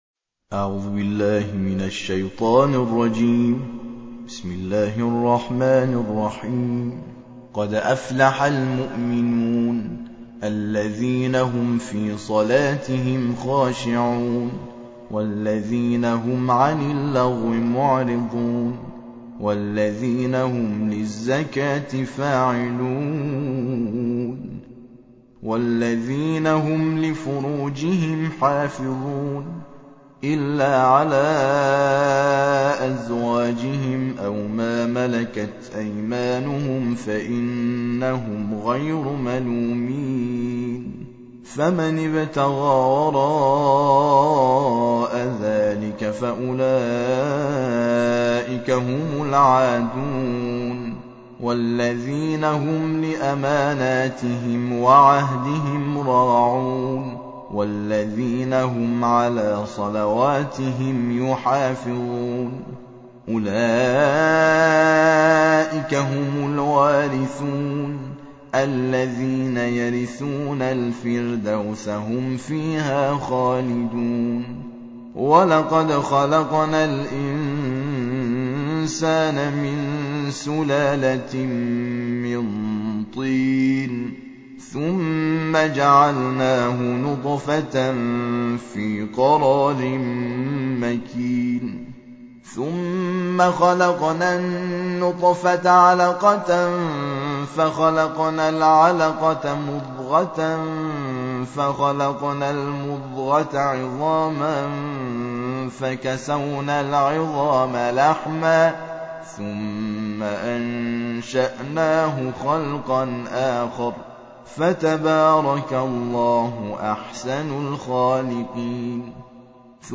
الجزء الثامن عشر / القارئ